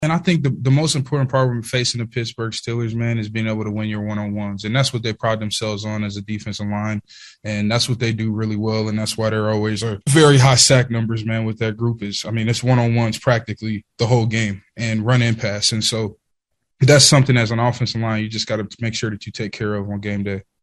Chiefs offensive lineman Orlando Brown says they will have to win the one on ones.